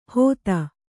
♪ hōta